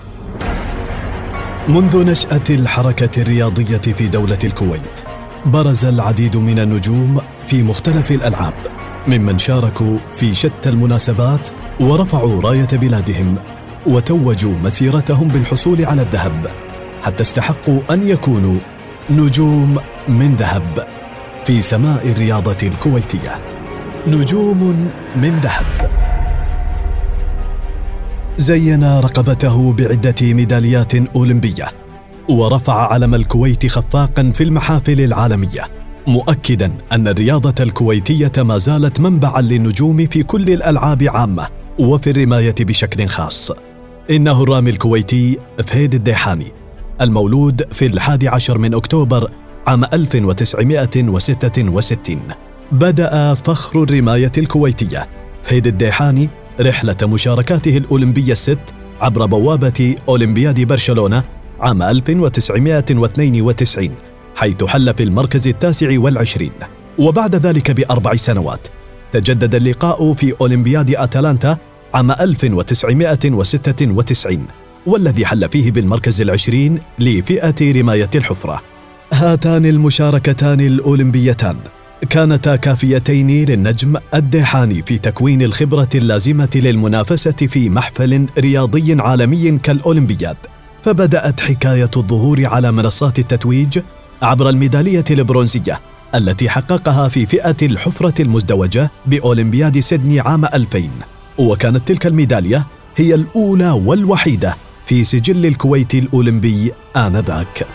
تعليق صوتي لبرنامج رياضي بعنوان نجوم من ذهب